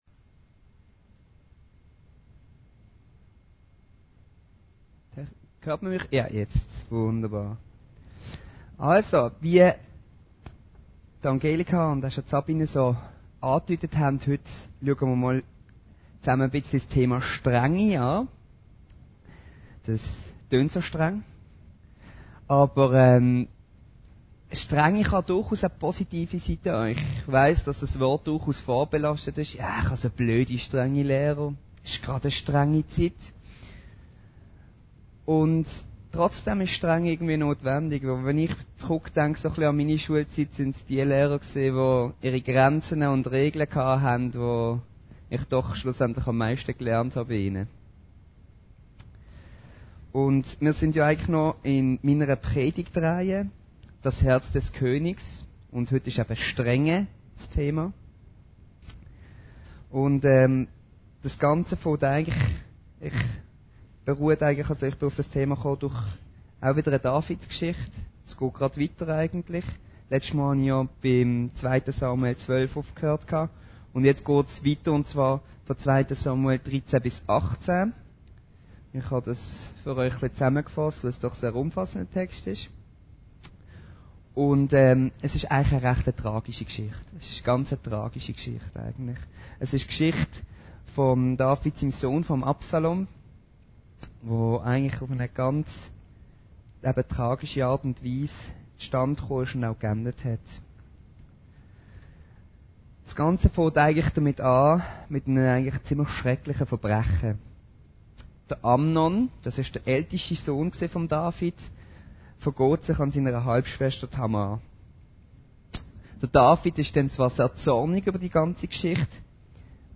Predigten Heilsarmee Aargau Süd – Das Herz des Königs: Strenge